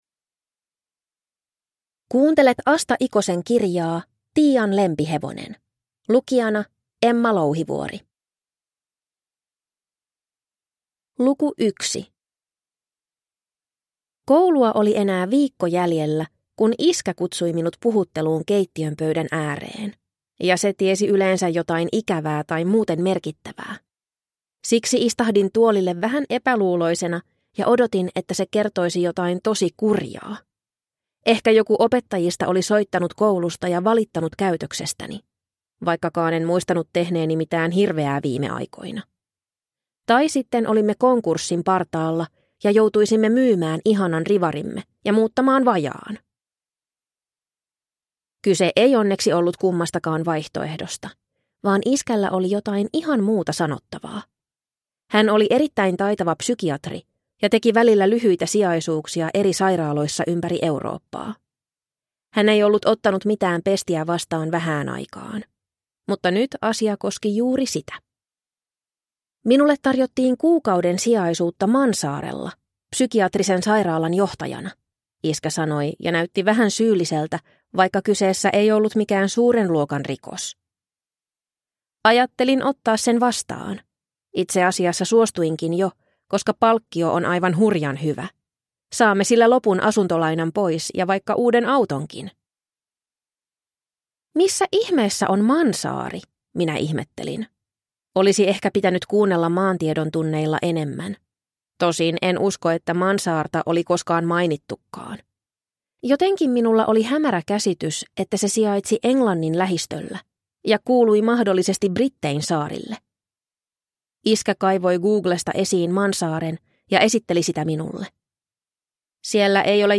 Tiian lempihevonen – Ljudbok